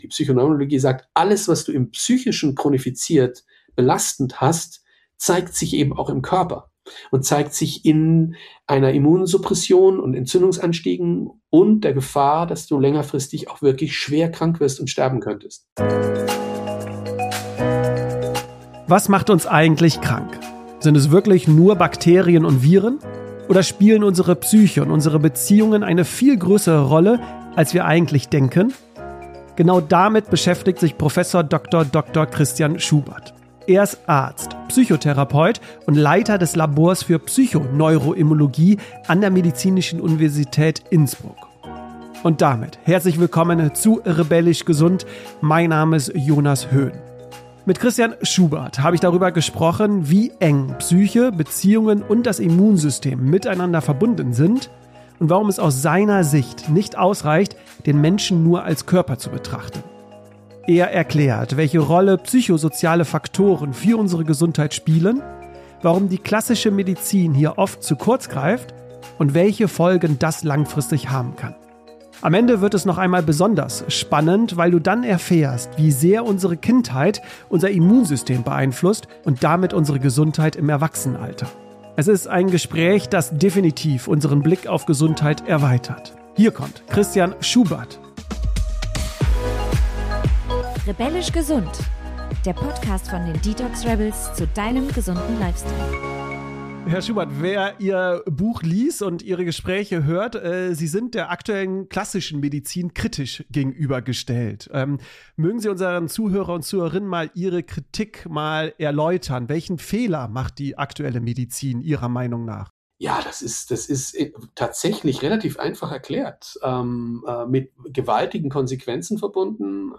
Besonders spannend wird es, wenn du erfährst, wie sehr unsere Kindheit unser Immunsystem beeinflusst – und damit unsere Gesundheit im Erwachsenenalter. Ein Gespräch, das definitiv den Blick auf Gesundheit erweitert.